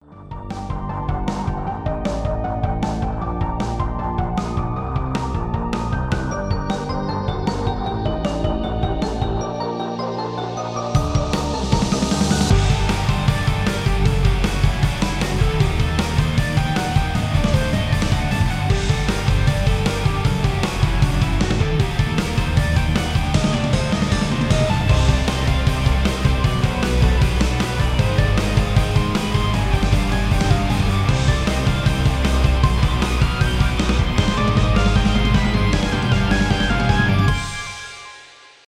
мистические , без слов , инструментальные